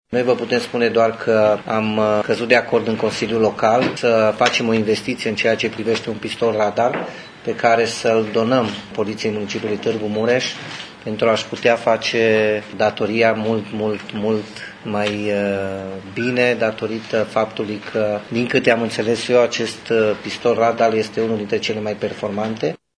Acesta a fost achiziţionat de Primăria Tîrgu-Mureş ca urmare a unei hotărâri de Consiliu Local, a explicat viceprimarul Claudiu Maior.